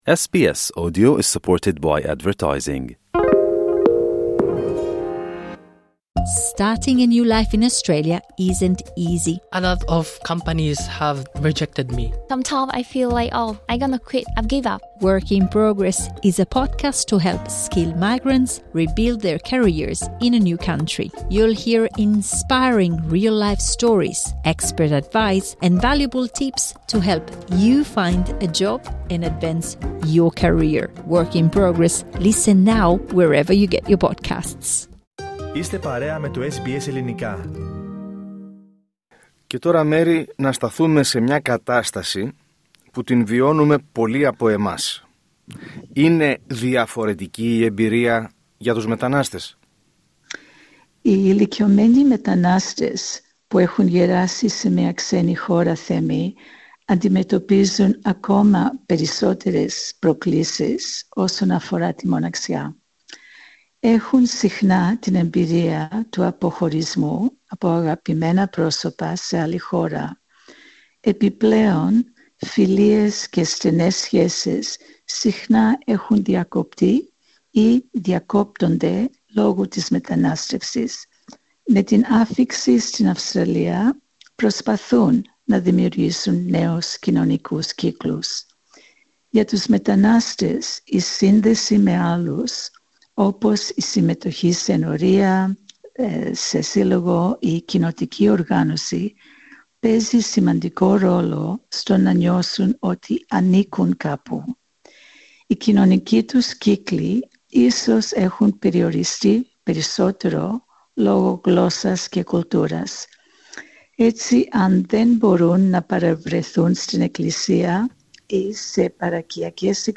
Η συνέντευξη είναι στο πλαίσιο ευρύτερης ενημερωτικής εκστρατείας του σταθμού μας αυτή την περίοδο, για την κοινωνική απομόνωση και την μοναξιά που αναδεικνύονται ως σοβαρά και πολυδιάστατα κοινωνικά ζητήματα με άμεσες επιπτώσεις στη σωματική και ψυχική υγεία..